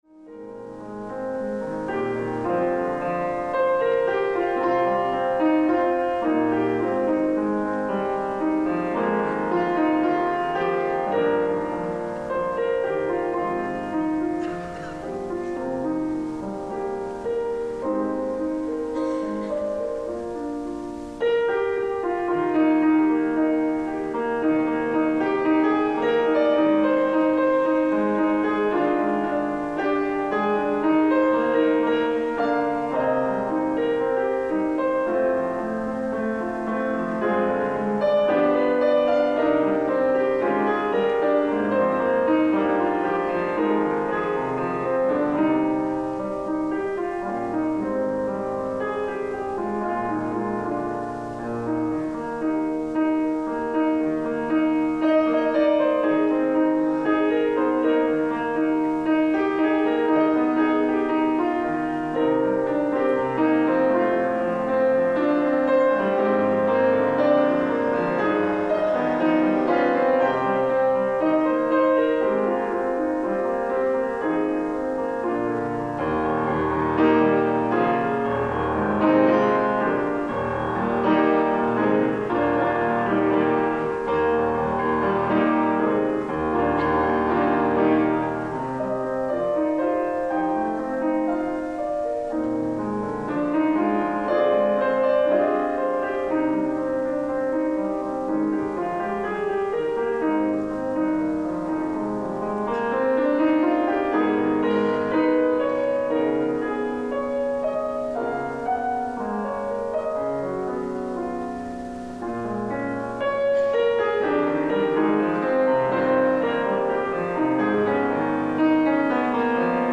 ライブ・アット・パレ･デ･ボザール、ブリュッセル、ベルギー 11/13/2015